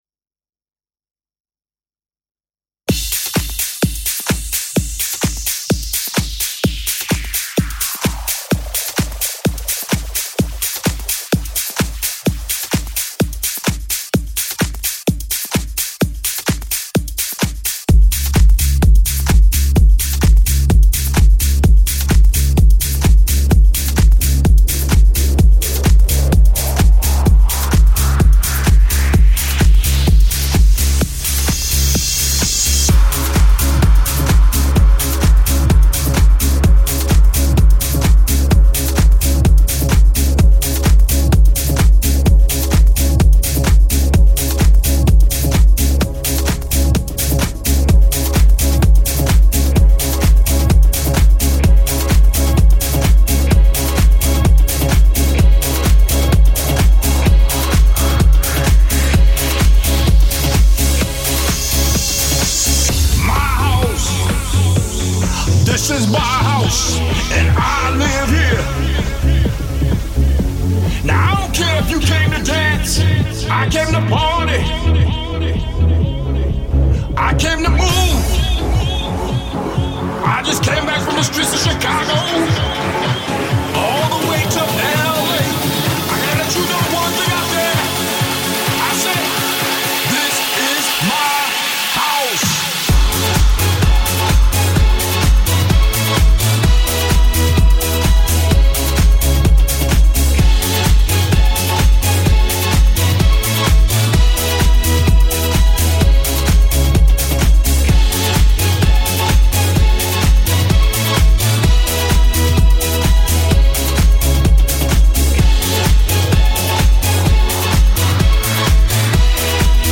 Disco House